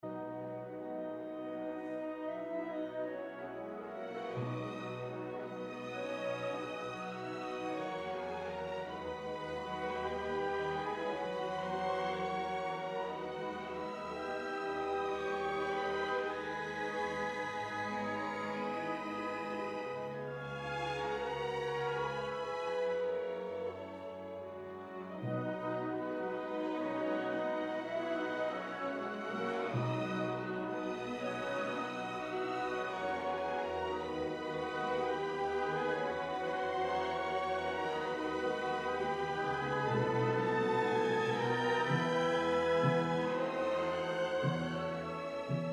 Much calmer and quieter. It starts with a trio for the solo-violin, the chimes and the harp.
This motif is developed as the violins enhance the music with canon-like entries. The rhythm becomes faster and the pitch increases as this part reaches its climax.
In terms of instrumentation Strauss chose, as it was usual for the Romantic period, a big orchestra, the instruments were the following: 3 flutes, English horn, 2 clarinets, 2 bassoons, contrabassoon, 4 horns, 3 trumpets, 3 trombones tuba, harp, timpani, triangle, cymbal, chime and strings. 10